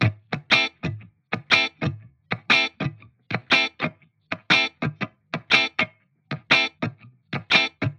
琥珀色的120Bpm吉他节拍
描述：Am, Ska, Reggae, Reaggaeton, Dub, Dubstep, Trap, Triptrop, Boombap, DrumandBass, DnB。
标签： 120 bpm Reggae Loops Guitar Electric Loops 1.35 MB wav Key : A FL Studio
声道立体声